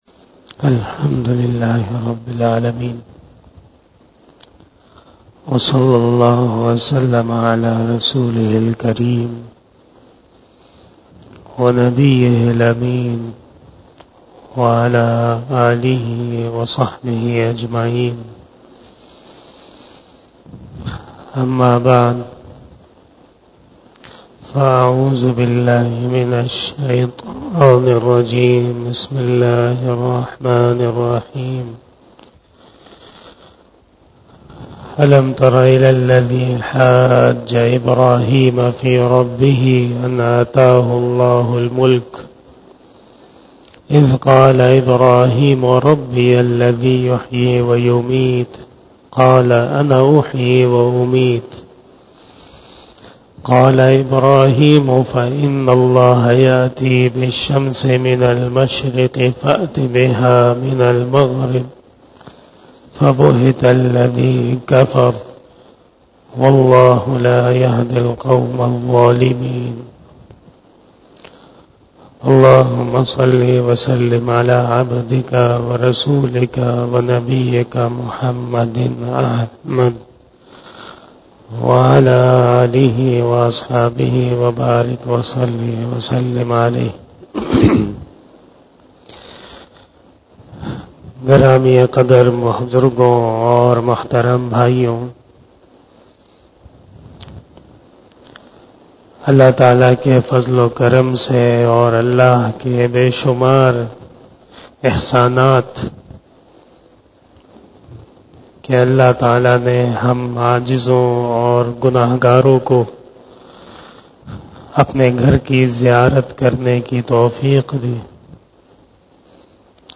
بیان شب جمعۃ المبارک 31 اگست 2023ء بمطابق 14 صفر 1445ھ